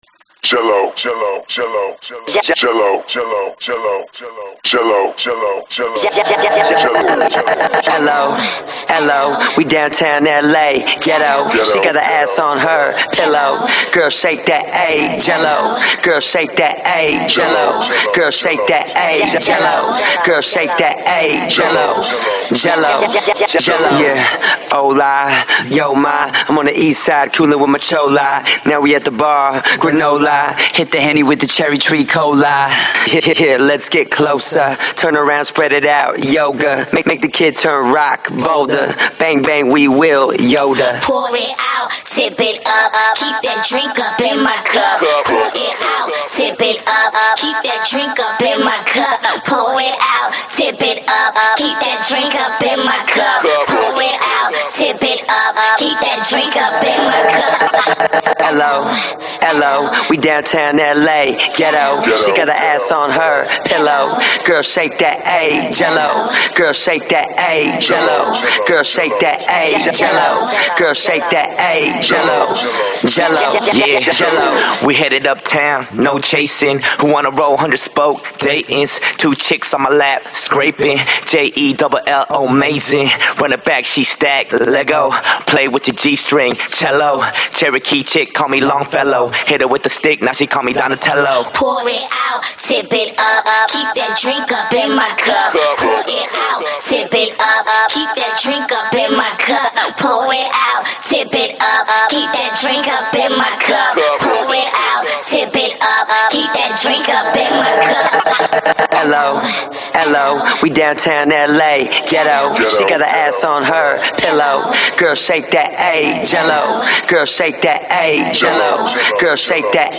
Официальная акапелла для вас!